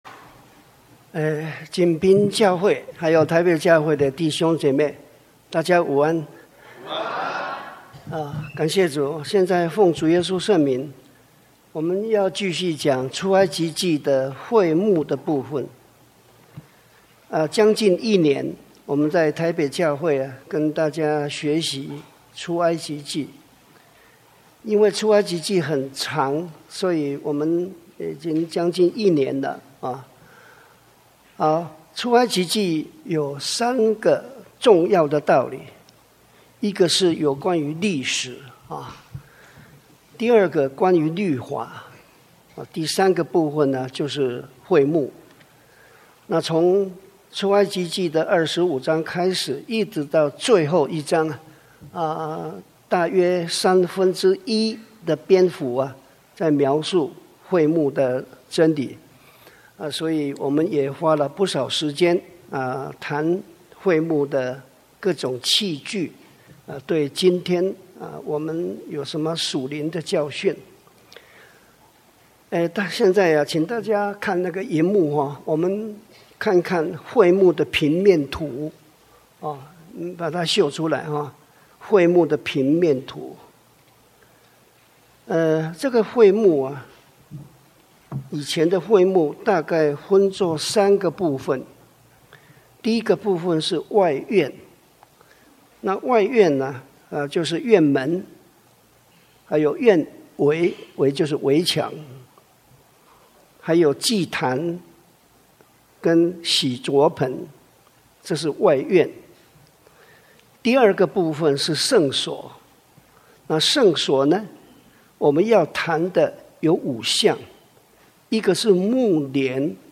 出埃及記(二十四)-講道錄音